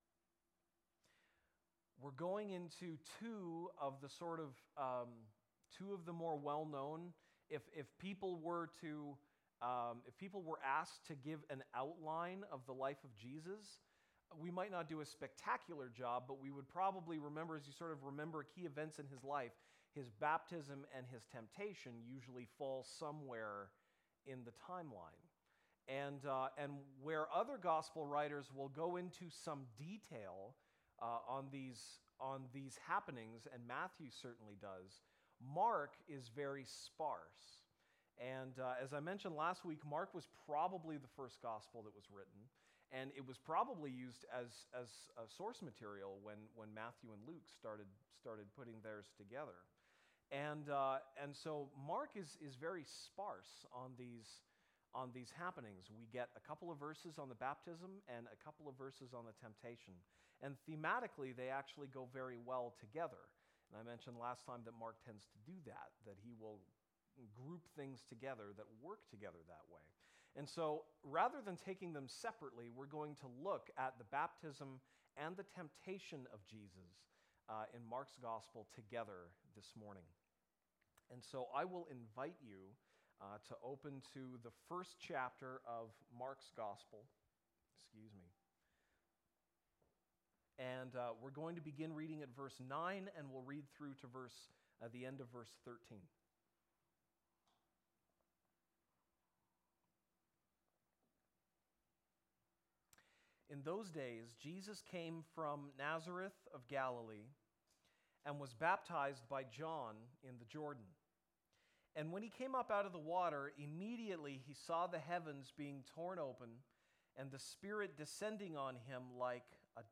Bible Text: Mark 1:9-13 | Preacher